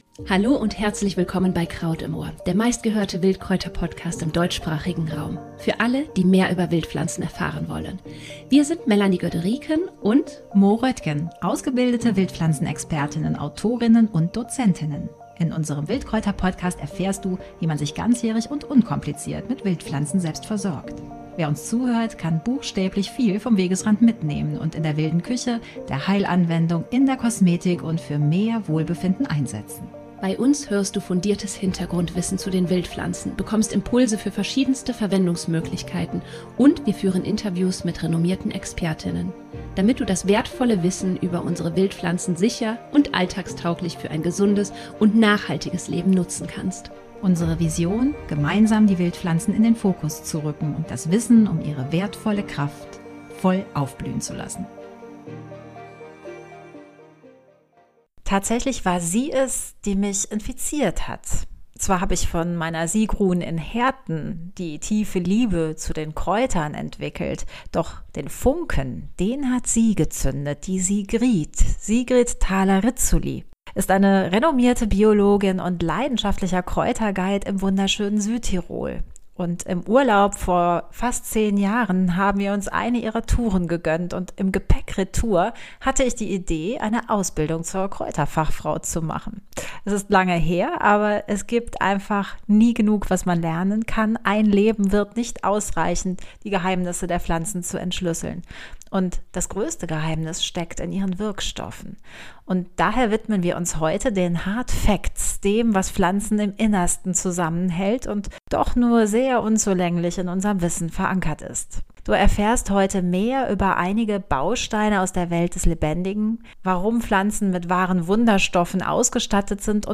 Kraut-Interview: 5 Herbstkräuter für die schöne Haut – Kraut im Ohr - Dein Wildkräuter Podcast – Lyssna här